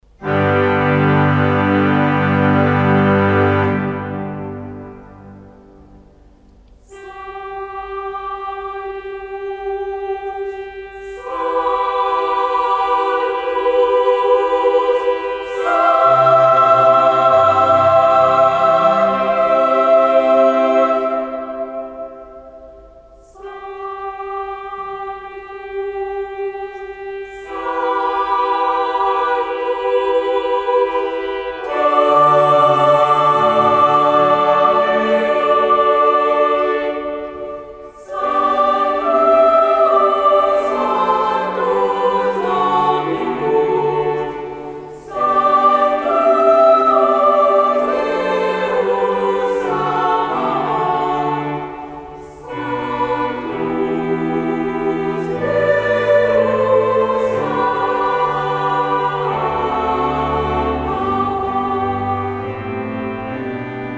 2 et 3 voix égales + orgue
Audios : version d'origine pour voix égales et orgue